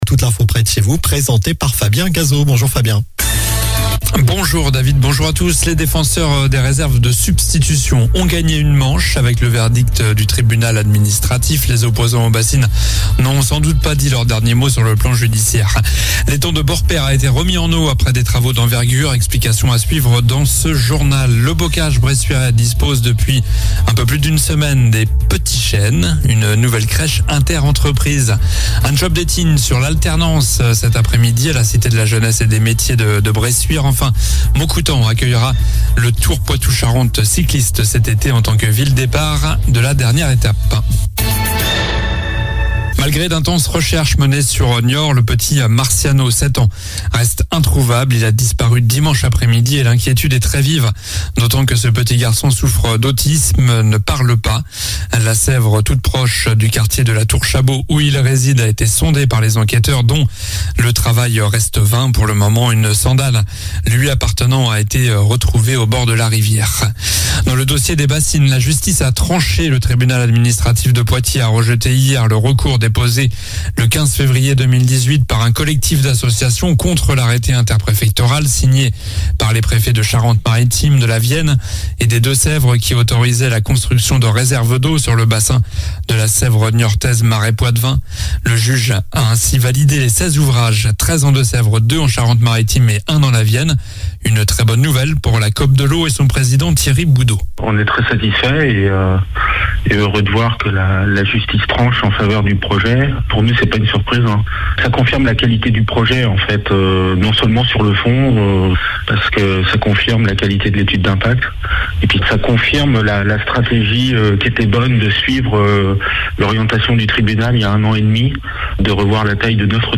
Journal du mercredi 12 avril (midi)